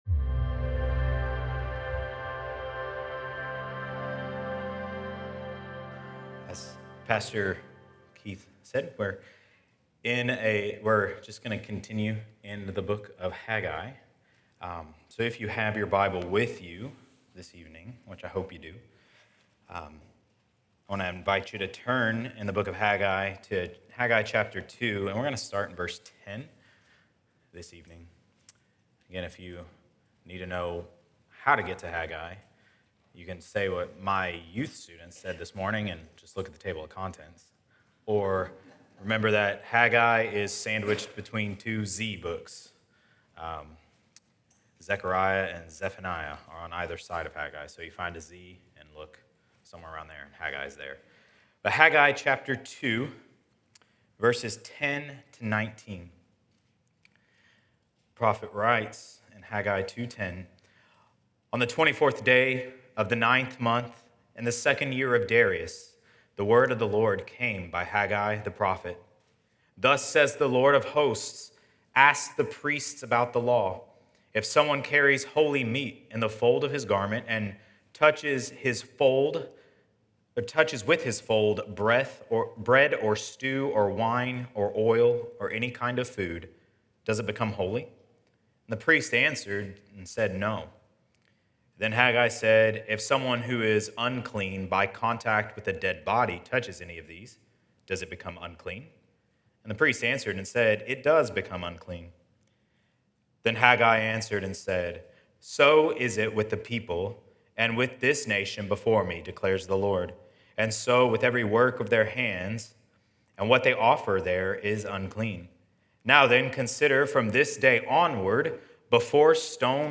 Explore other Sermon Series